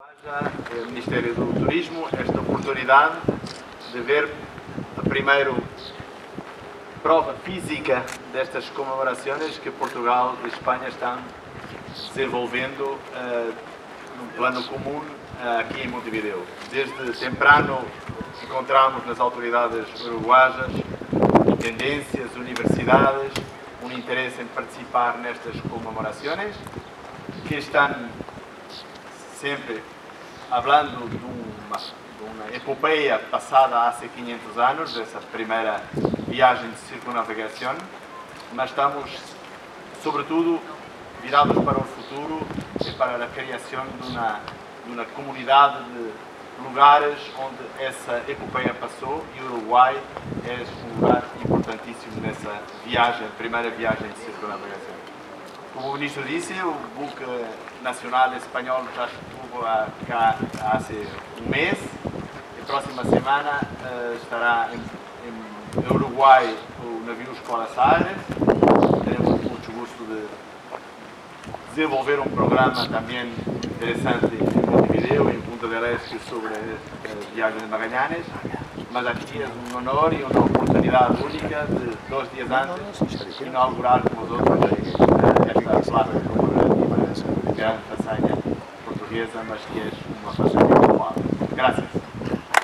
Posteriormente en la Punta de San Pedro, se llevó a cabo un acto en el que se descubrió una placa conmemorativa a Magallanes, al pie del mástil del Pabellón Nacional.
Ministro de Turismo Benjamin Liberoff